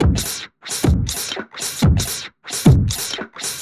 Index of /musicradar/uk-garage-samples/132bpm Lines n Loops/Beats
GA_BeatDSweepz132-04.wav